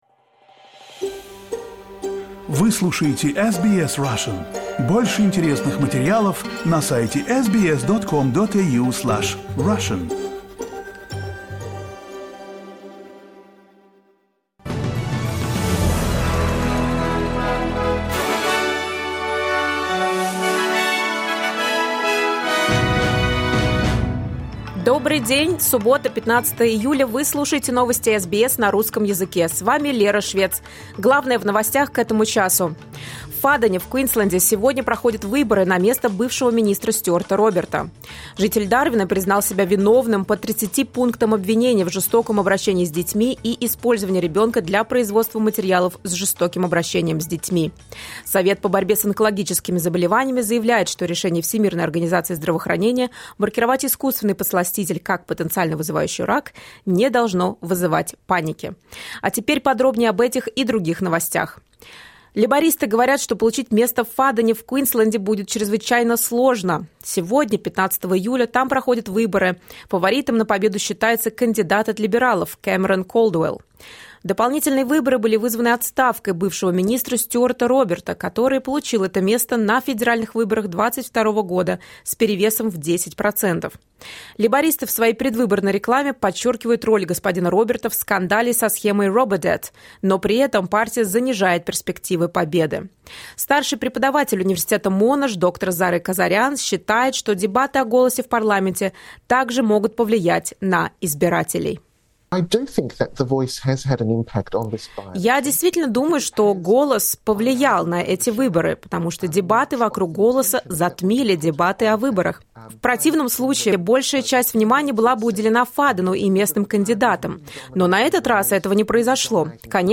SBS news in Russian — 15.07.2023